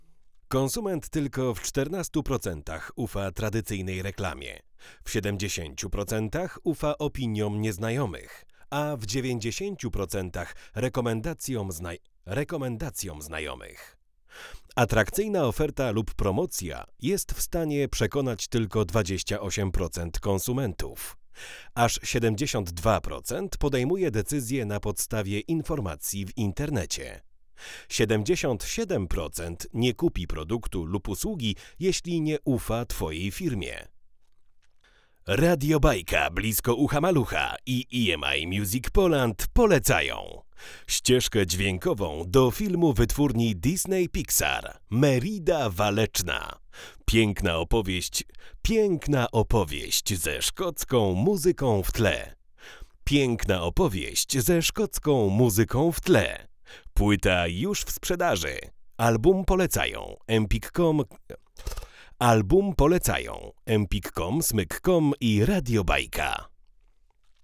Mocno osadzony, przejrzysty, ale jednocześnie nie wyostrzony dźwięk, którego szuka każdy realizator.